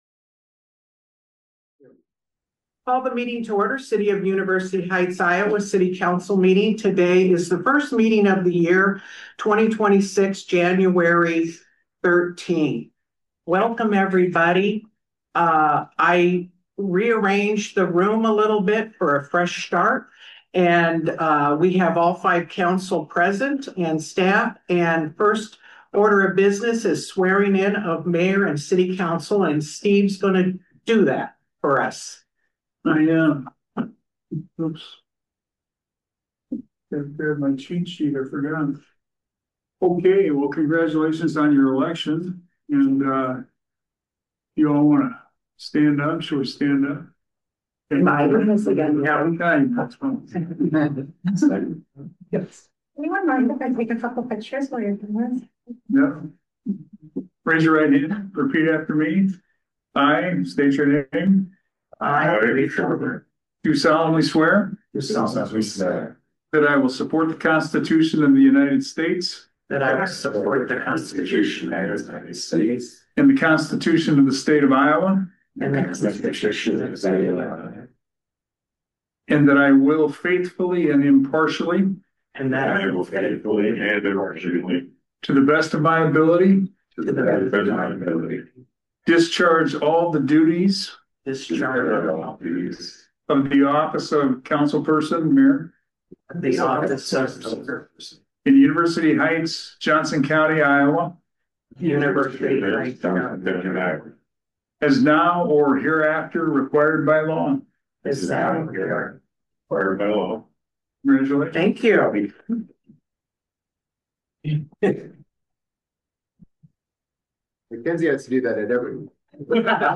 The monthly meeting of the University Heights City Council.